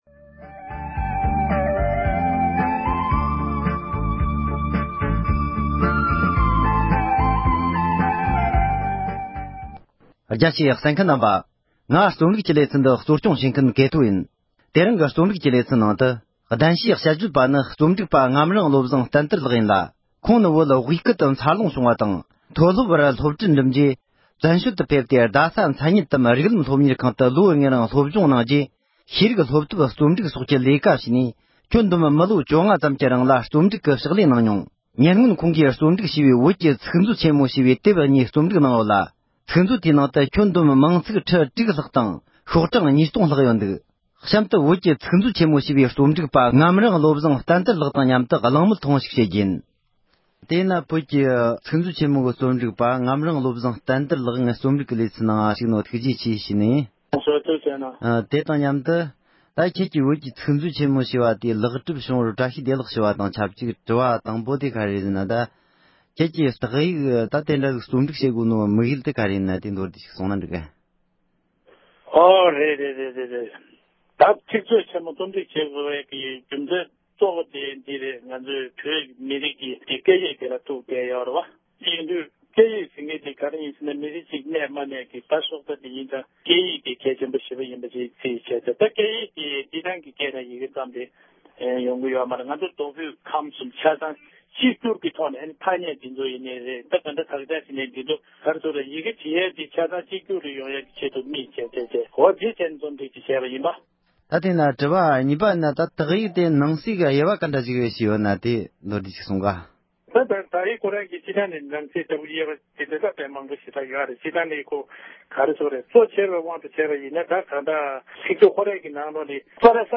བཅར་འདྲི་ཞུས་པ།